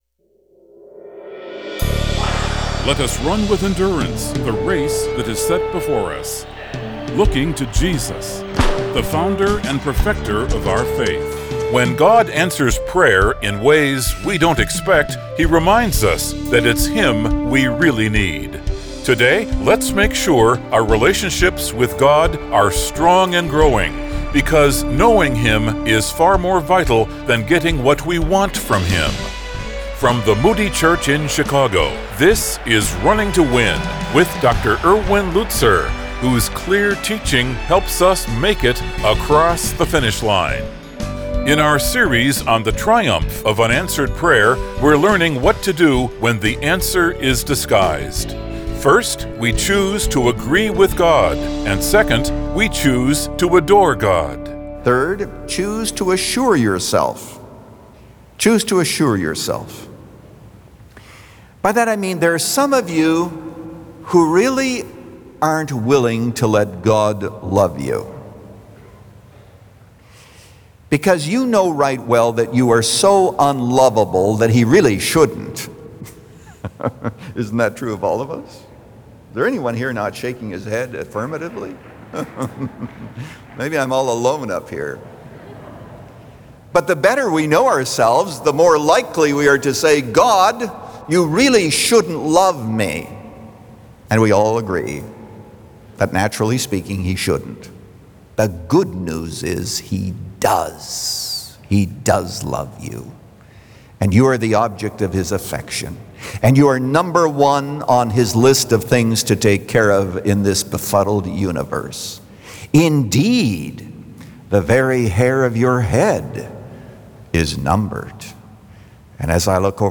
In this message, Pastor Lutzer shares five choices that develop intimacy during our quiet times.
But with the Bible front and center and a heart to encourage, Pastor Erwin Lutzer presents clear Bible teaching, helping you make it across the finish line.